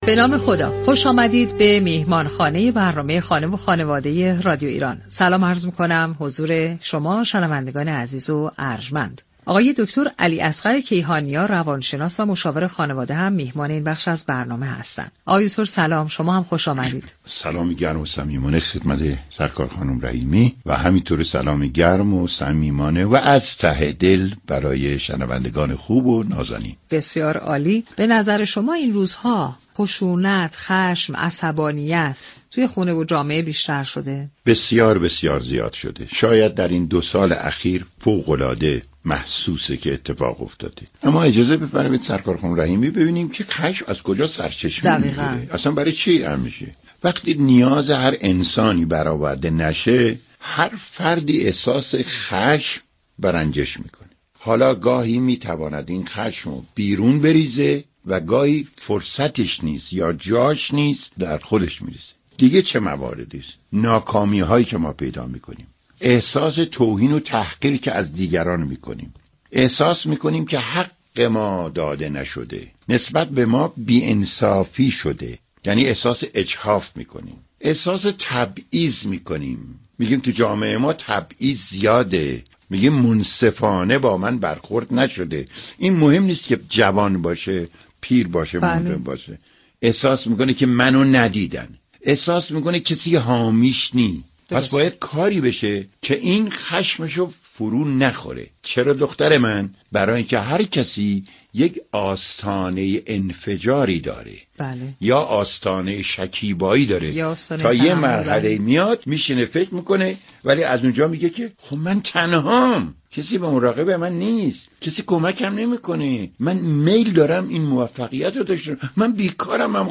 به اتفاق این این گفت و گو را بشنویم